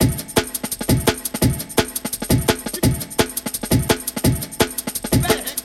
Tag: 170 bpm Drum And Bass Loops Drum Loops 975.40 KB wav Key : Unknown